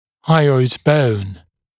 [ˈhʌɪɔɪd bəun][ˈхайойд боун]подъязычная кость